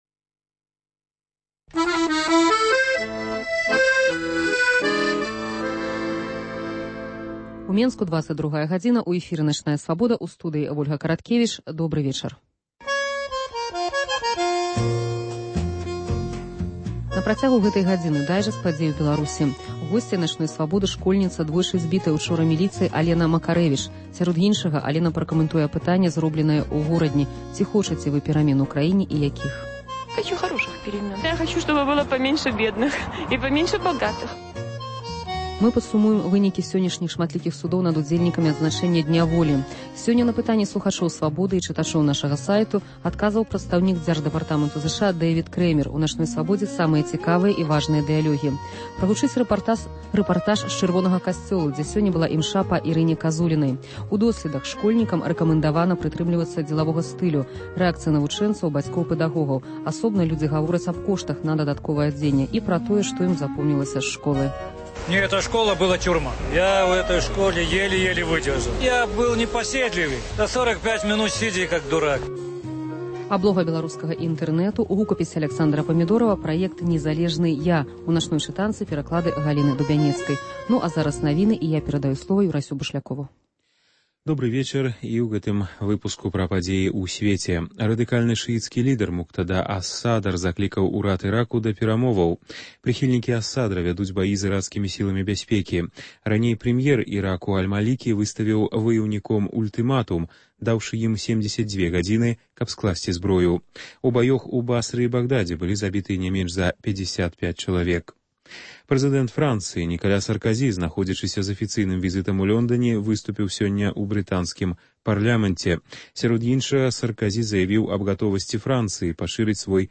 * Апытаньне ў Горадні: ці хочаце вы перамен у краіне?